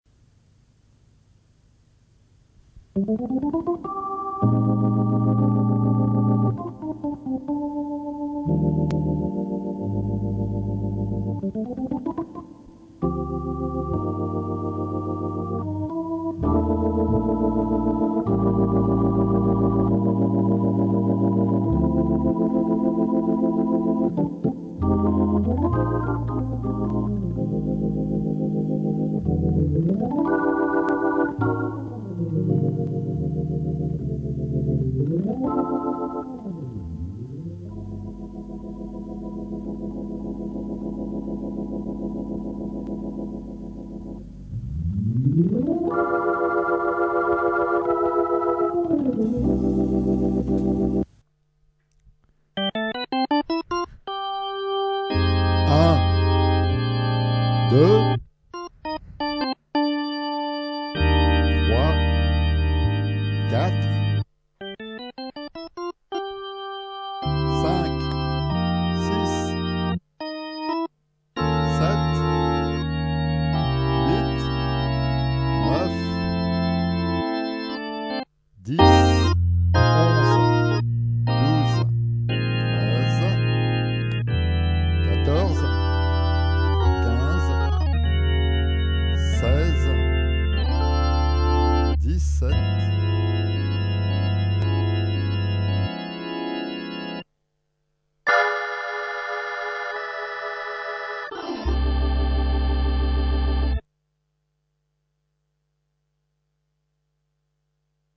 Jouée par Don PATTERSON, c’est à mon avis, une ballade des plus flamboyantes à l’orgue Hammond
Et si ça plait, on écoute la seconde partie dans laquelle je donne au fur et à mesure le numéro correspondant à la composition des accords (notés de la note la plus grave à la plus aigue)…pas besoin de savoir lire la musique.